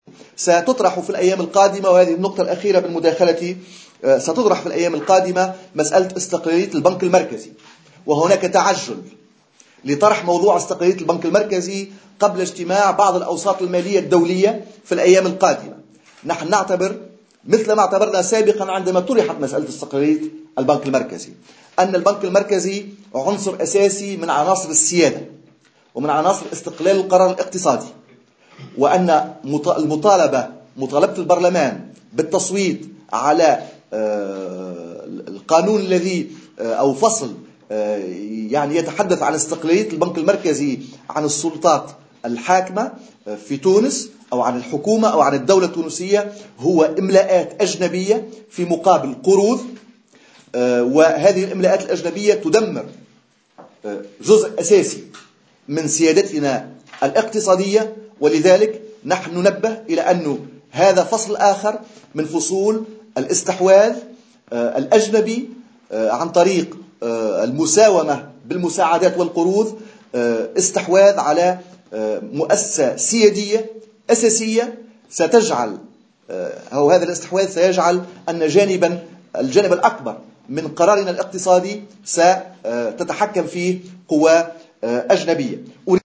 وأضاف منصر خلال ندوة صحفية عقدها الحزب اليوم أنه تم التعجيل بطرح هذا الموضوع قبل اجتماع بعض الأوساط المالية في الأيام القادمة، مشيرا إلى أن هذه الإملاءات الأجنبية ستدمر جزءا أساسيا من سيادة تونس الاقتصادية وتمثل شكلا من أشكال الاستحواذ الأجنبي عن طريق المساومة بالمساعدات والقروض على مؤسسة سيادية أساسية، حسب قوله.